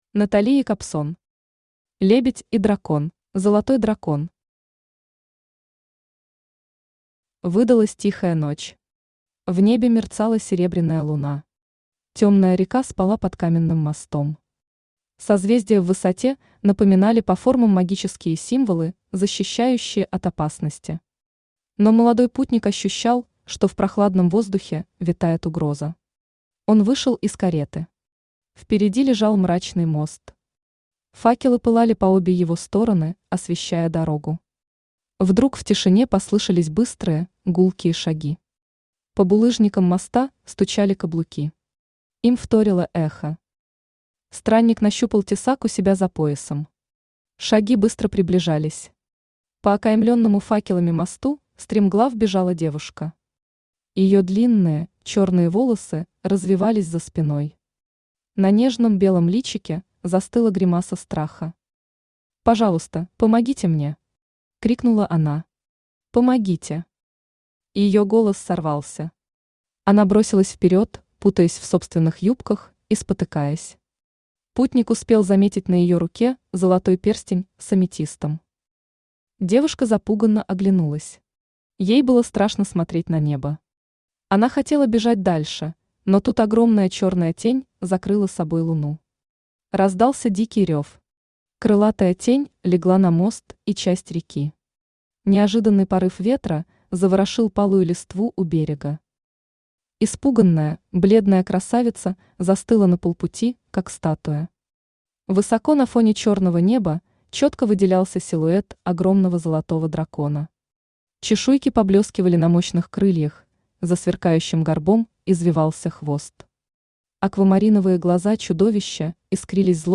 Аудиокнига Лебедь и дракон | Библиотека аудиокниг
Aудиокнига Лебедь и дракон Автор Натали Альбертовна Якобсон Читает аудиокнигу Авточтец ЛитРес.